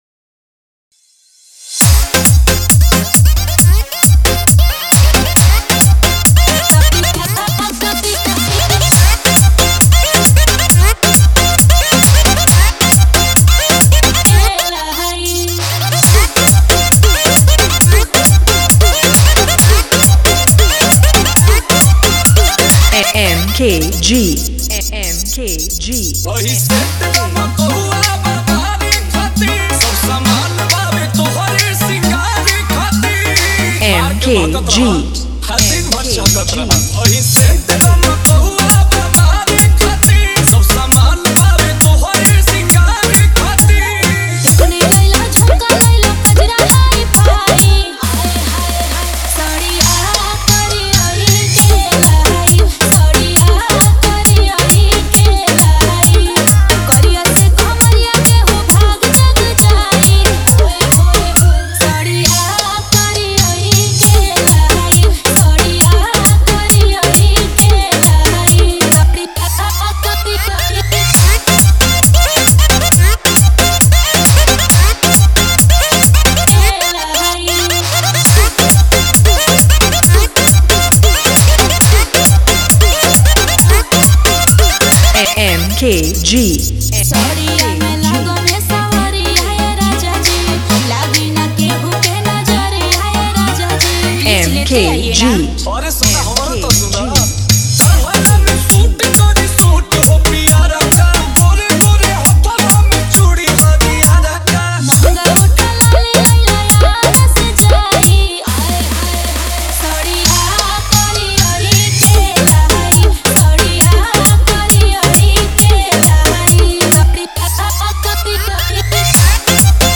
2025 Bhojpuri DJ Remix - Mp3 Songs